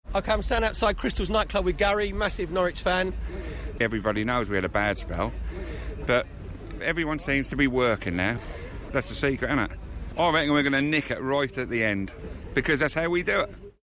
Norwich fan prediction ahead of match v Palace